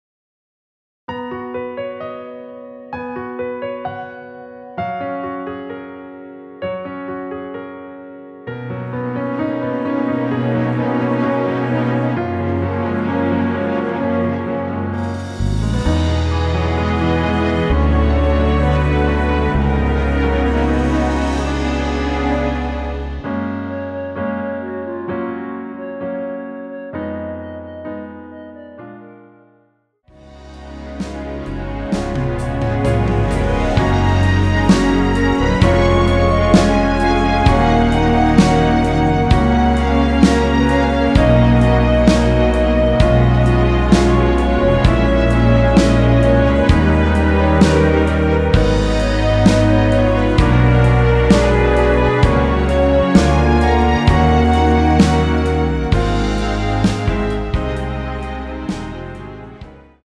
원키에서(-2)내린 멜로디 포함된 MR입니다.
Gb
앞부분30초, 뒷부분30초씩 편집해서 올려 드리고 있습니다.